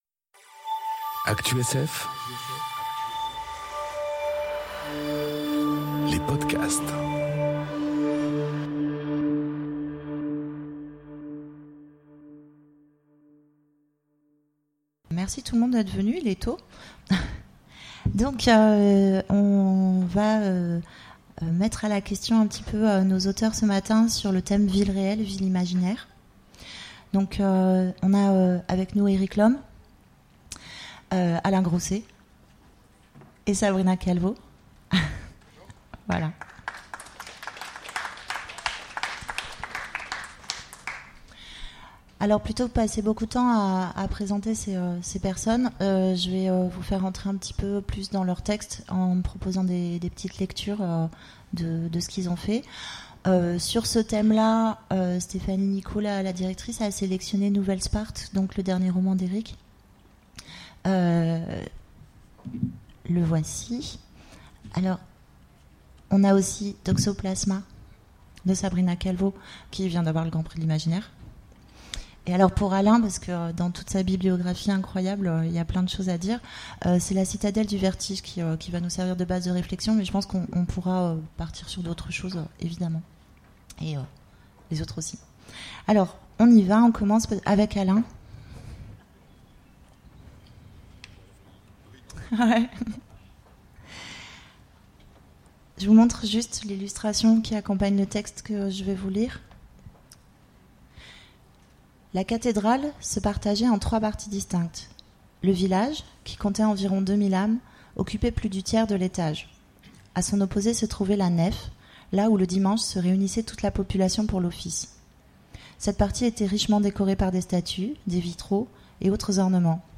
Conférence Villes réelles... villes imaginaires ! enregistrée aux Imaginales